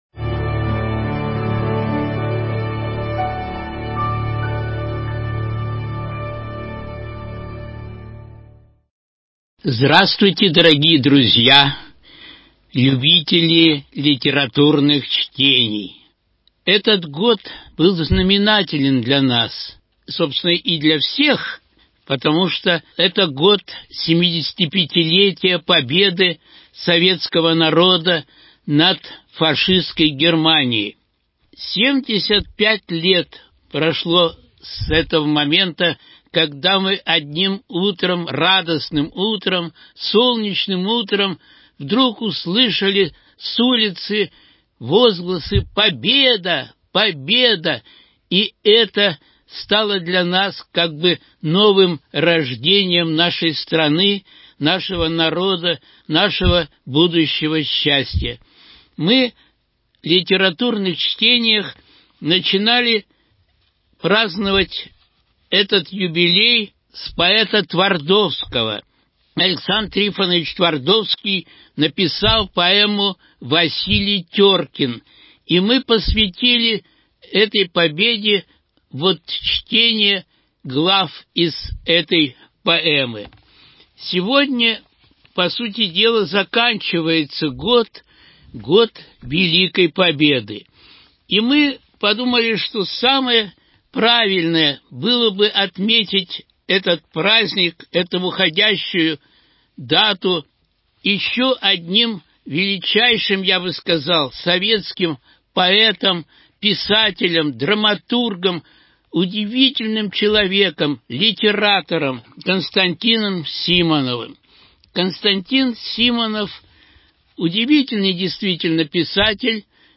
Литературные чтения: Стихи Константина Симонова о войне 15.12.2020